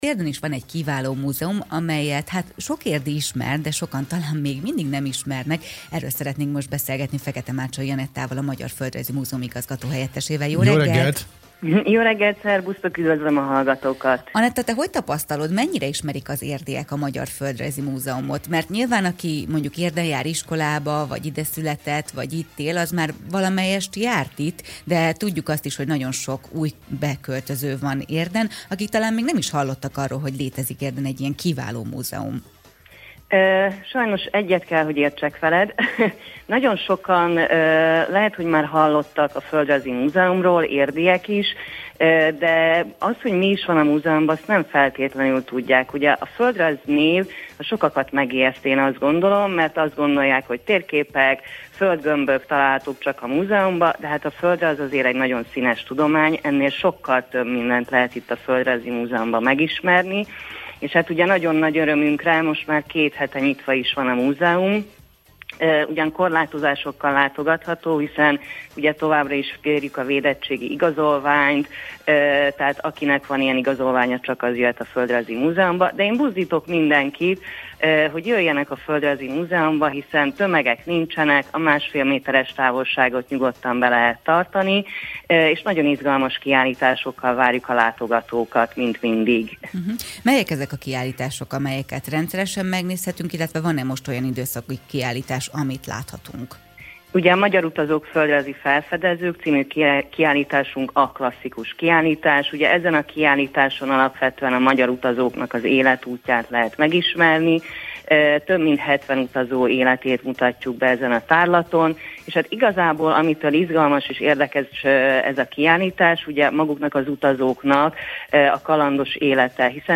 Az Érd FM 101.3 interjúja